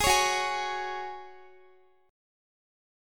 Listen to Gbsus2 strummed